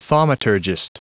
Prononciation du mot thaumaturgist en anglais (fichier audio)
thaumaturgist.wav